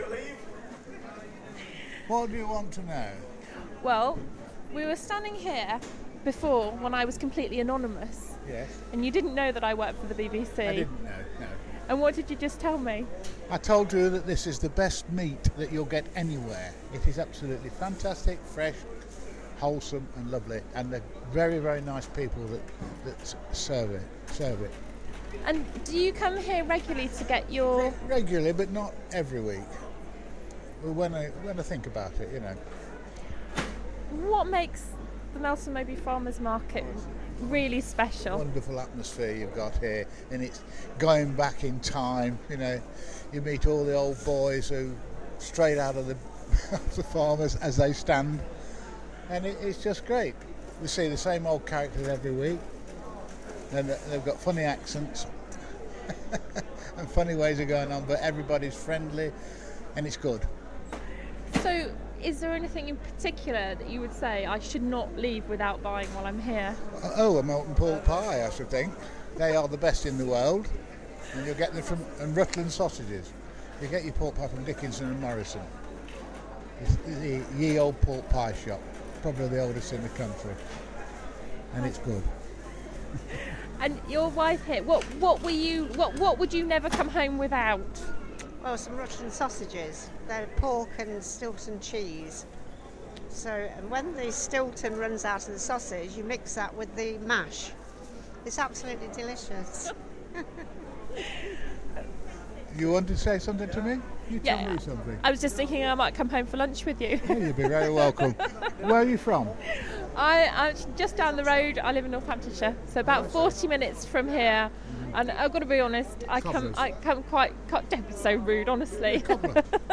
I popped along to find out more about this fantastic market.